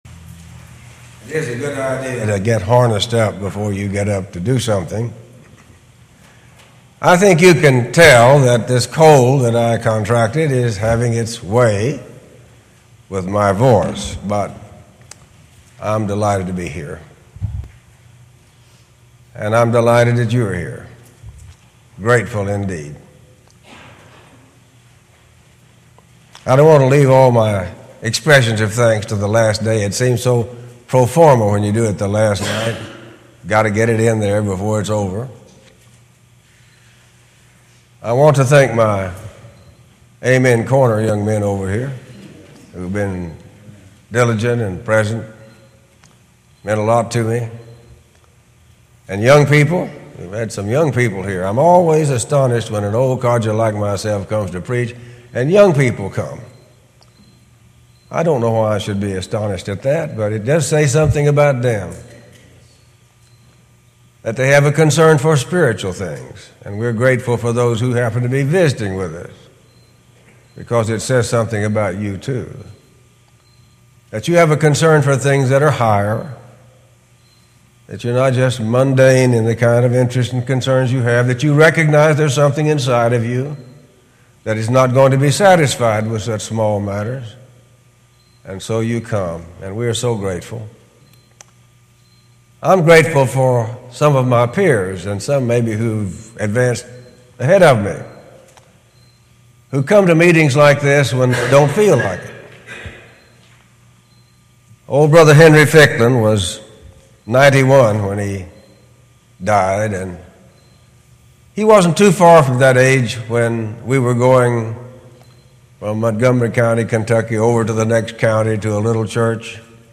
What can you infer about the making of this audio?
Series: Gettysburg 2013 Gospel Meeting